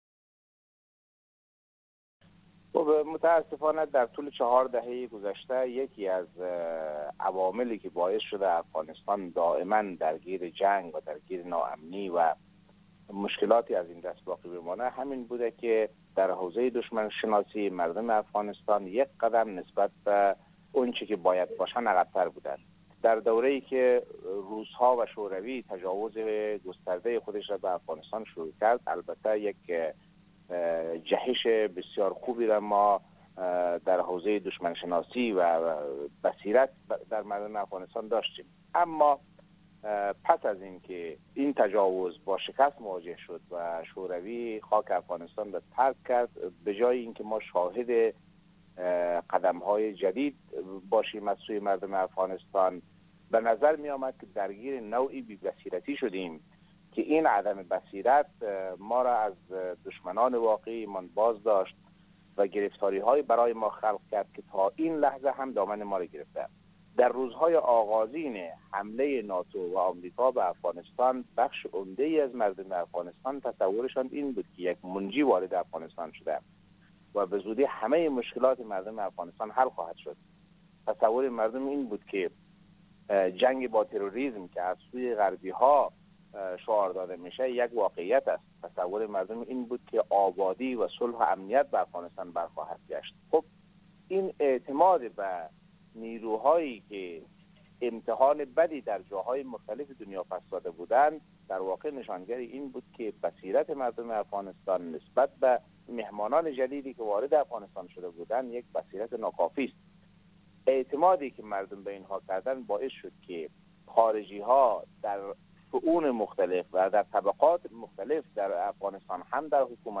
در گفت و گو با خبرنگار رادیو دری